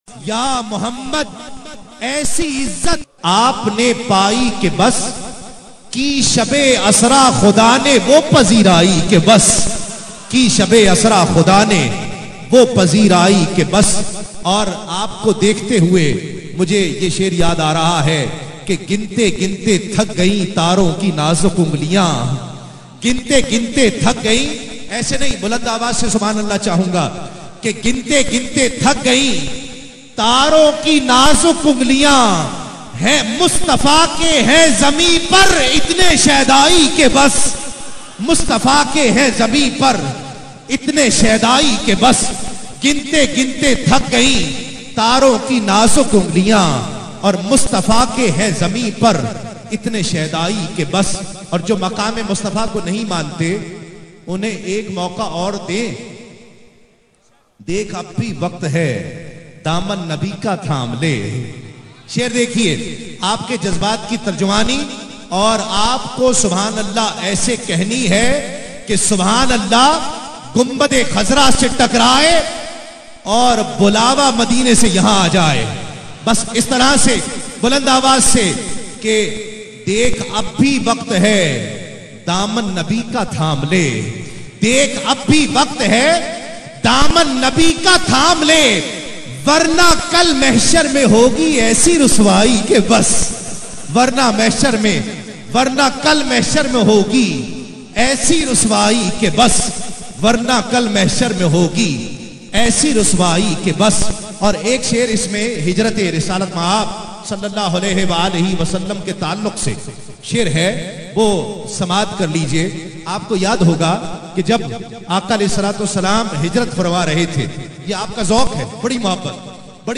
Urdu Naat
Naat in a Heart-Touching Voice